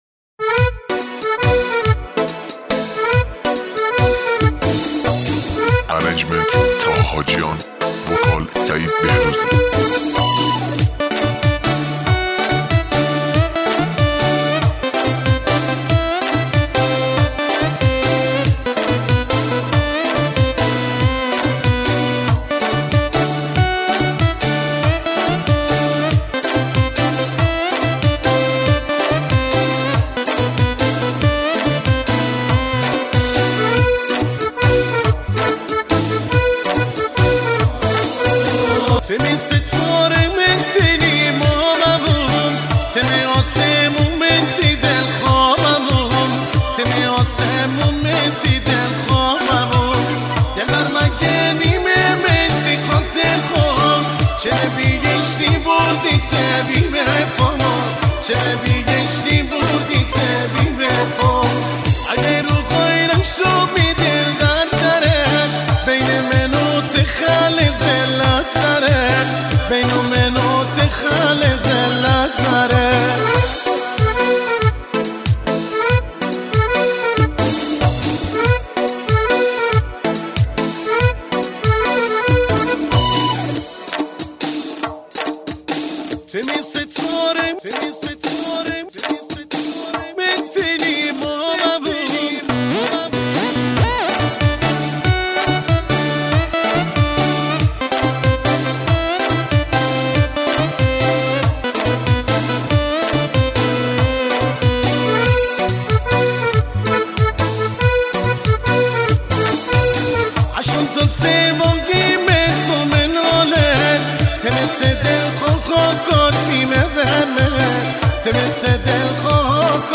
آهنگ متفاوت مازندرانی
آهنگ شاد